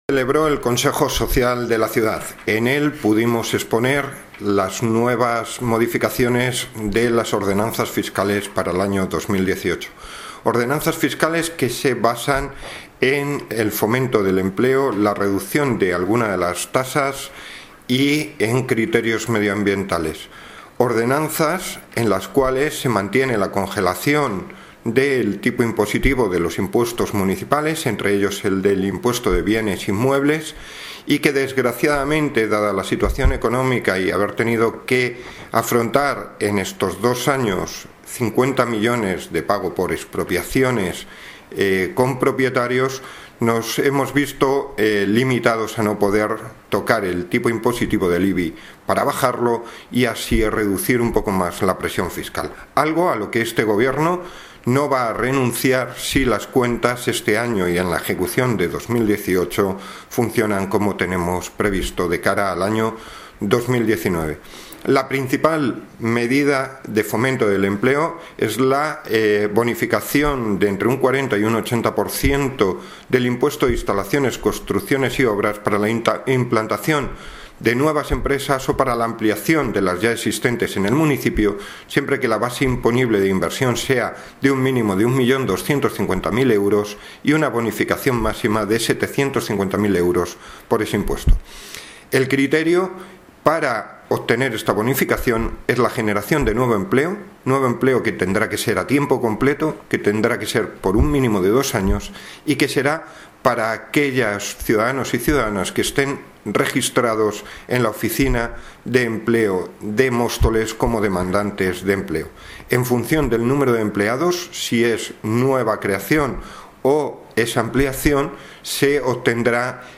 Audio - Javier Gómez (Concejal de Hacienda y Patrimonio) Sobre informe favorable modificación Ordenanzas
Audio - Javier Gómez (Concejal de Hacienda y Patrimonio) Sobre informe favorable modificación Ordenanzas.mp3